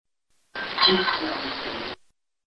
Harney Mansion EVP
The raw but slightly amplified EVP